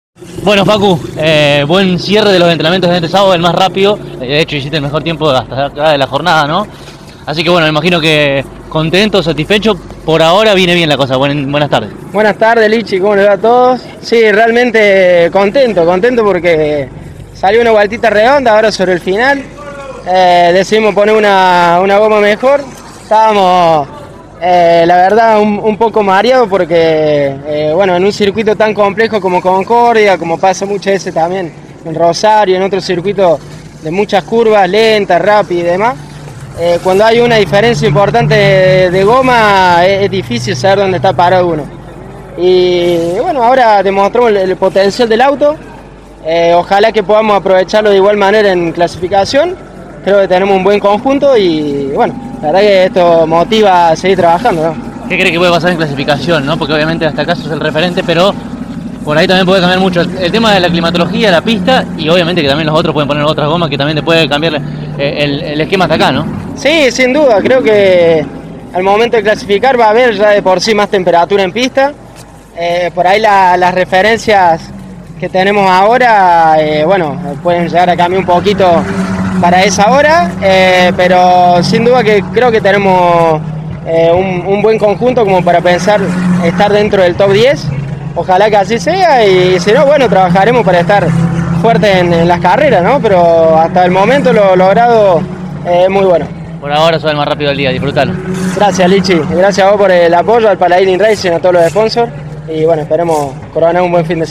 en diálogo de manera exclusiva con CÓRDOBA COMPETICIÓN: